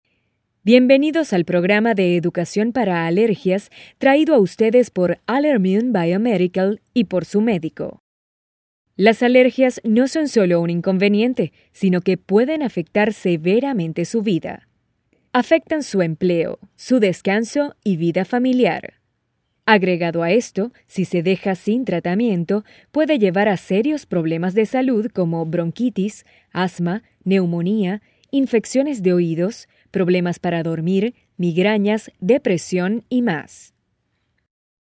Versatile, 8 years experience. Uplifting, friendly, announcer, corporate, maternal, sexy, sensual, funny, calming, serious, informative
spanisch SĂŒdamerika
Sprechprobe: eLearning (Muttersprache):